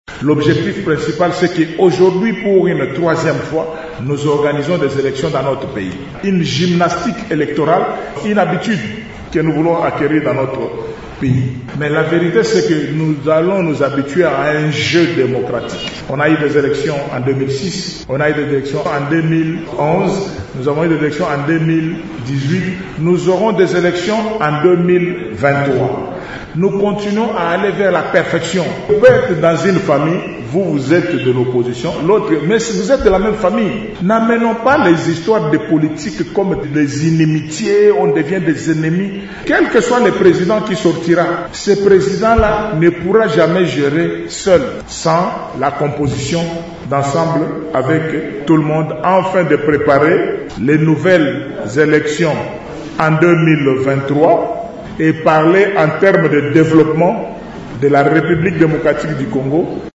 Denis Kambayi l’a déclaré vendredi 4 janvier à Kananga, lors d’une réunion avec les membres des partis politiques, les chefs coutumiers et les représentants de la société civile.